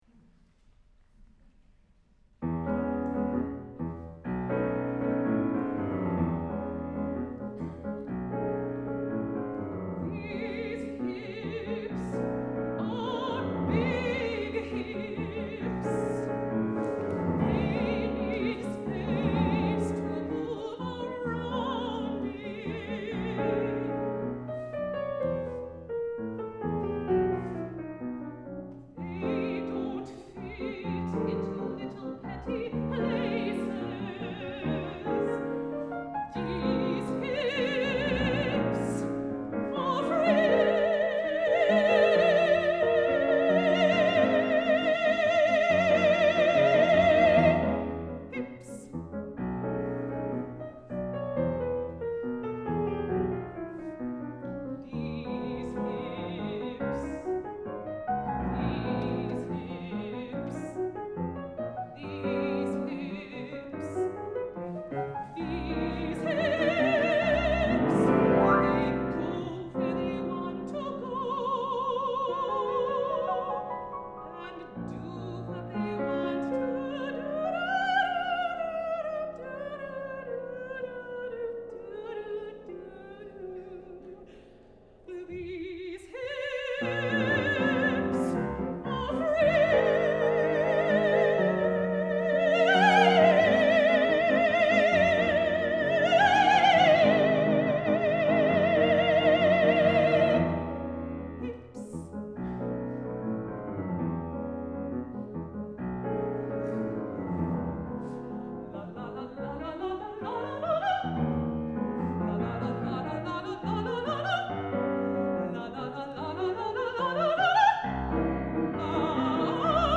for Mezzo-soprano and Piano (1997)